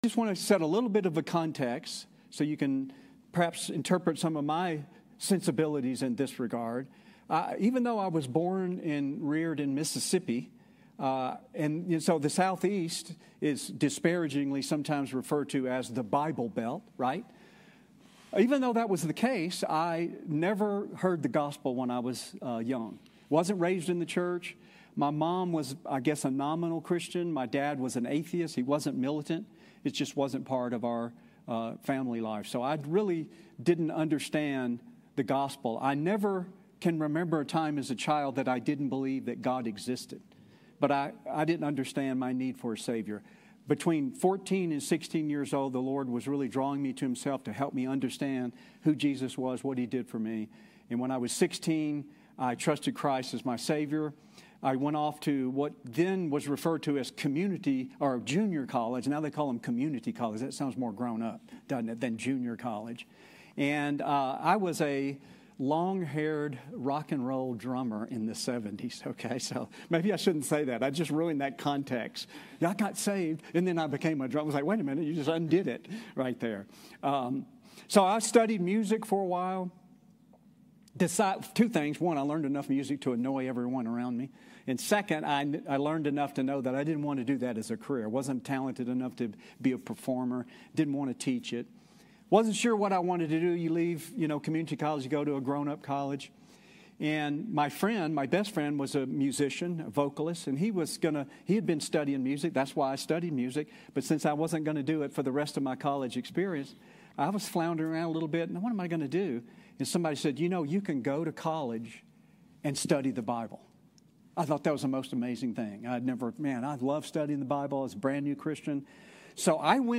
Sermons | Redemption Hill Church